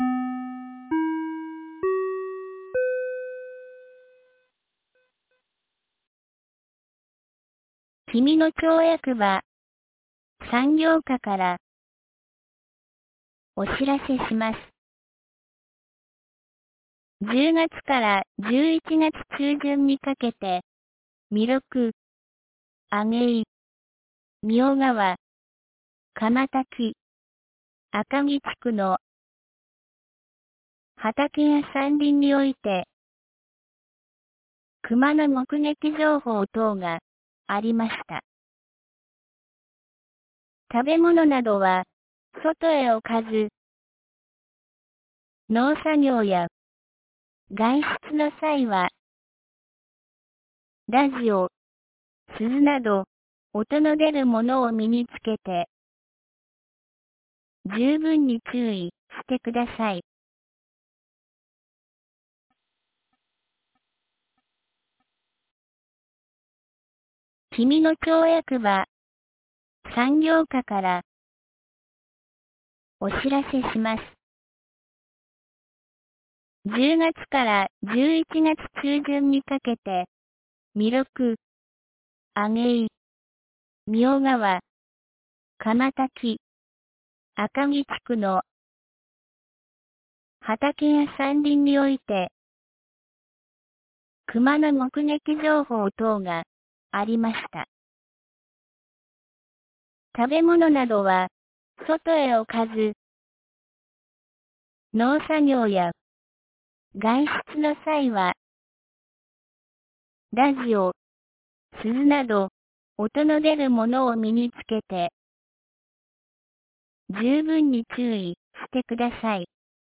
2024年11月29日 17時07分に、紀美野町より下神野地区、上神野地区へ放送がありました。